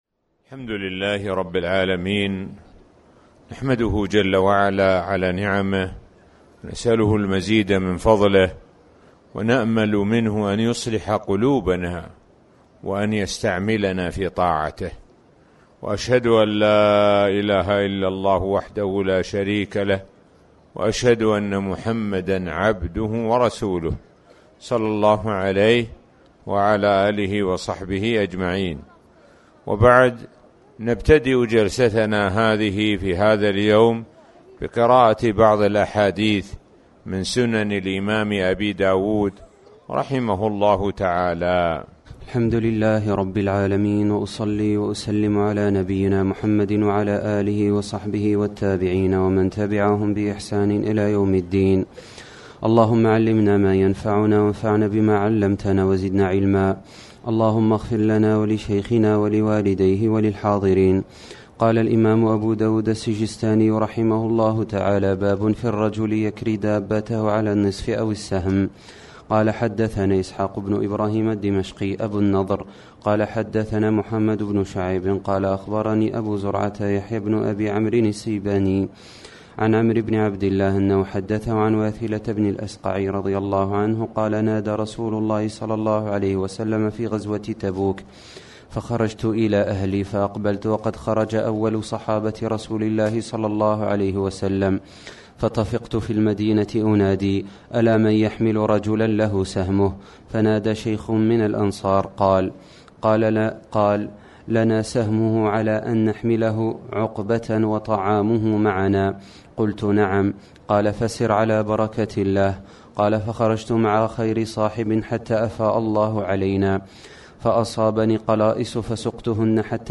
تاريخ النشر ١٣ رمضان ١٤٣٩ هـ المكان: المسجد الحرام الشيخ: معالي الشيخ د. سعد بن ناصر الشثري معالي الشيخ د. سعد بن ناصر الشثري كتاب الجهاد